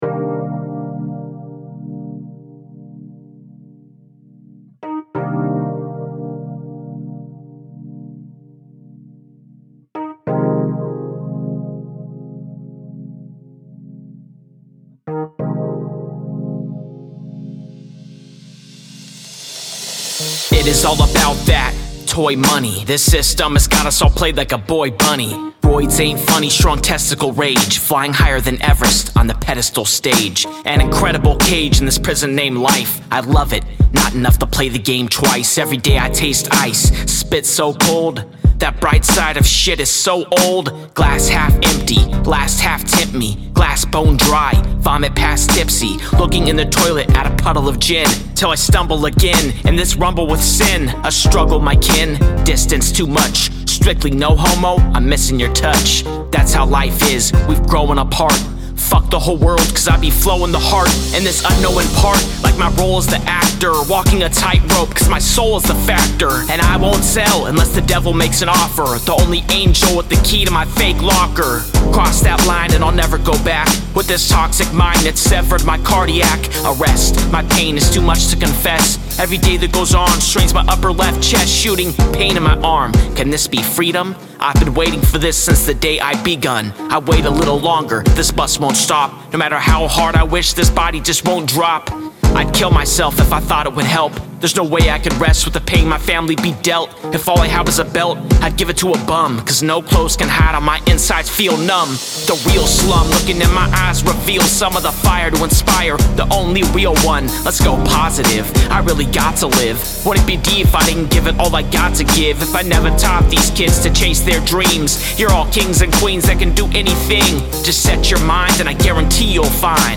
Recorded at AD1 Studios